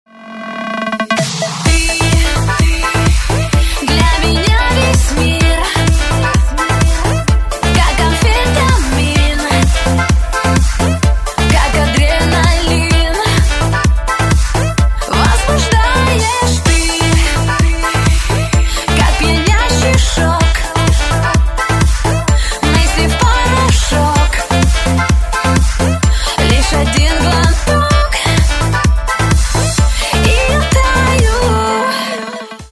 громкие
dance
Electronic
EDM
электронная музыка
electro house